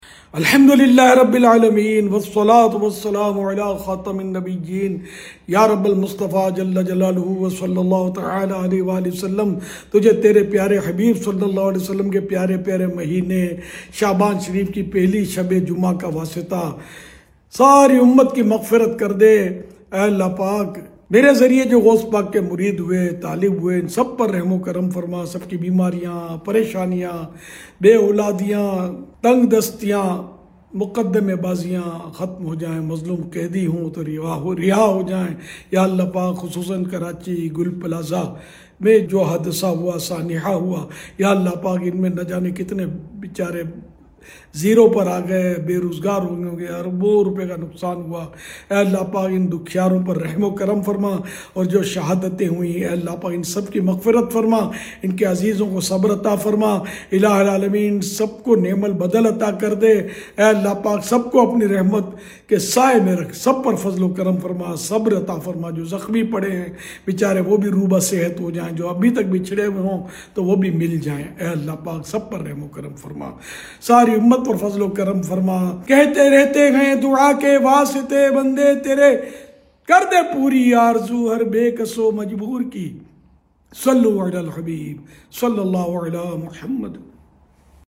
Vocolist